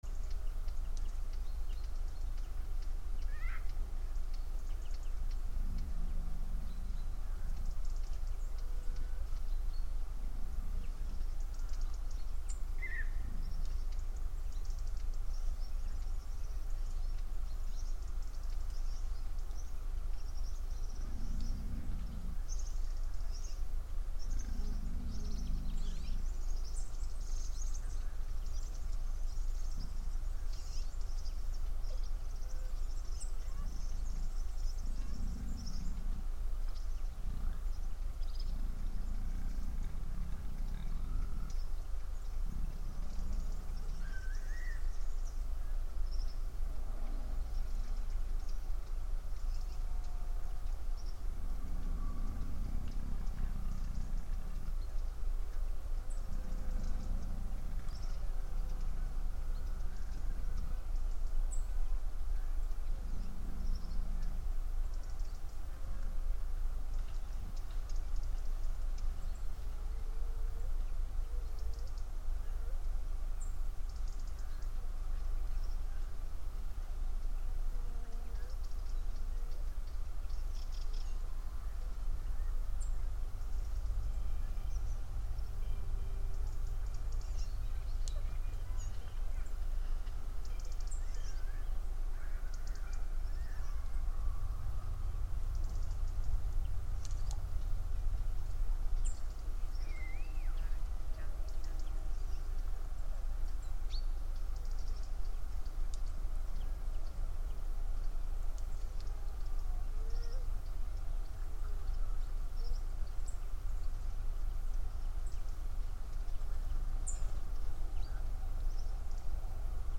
Live from Soundcamp: associacio so live transmission (Audio)